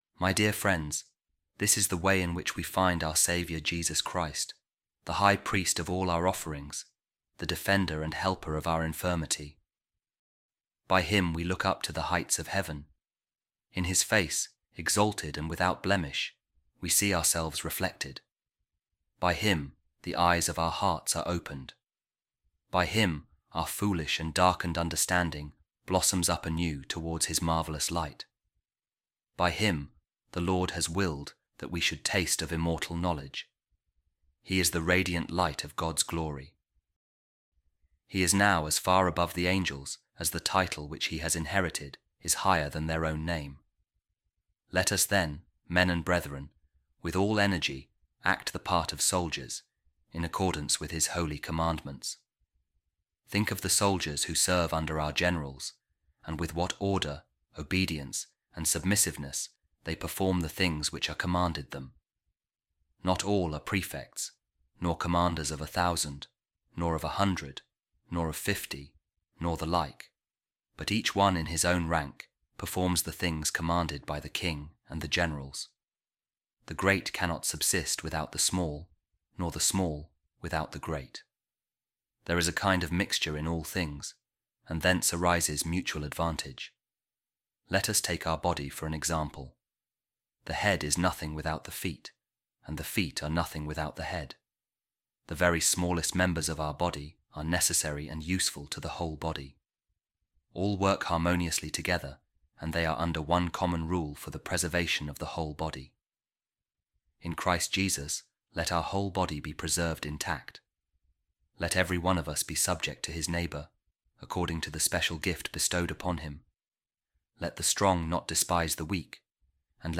A Reading From The Letter Of Pope Saint Clement I To The Corinthians | Jesus Is The Way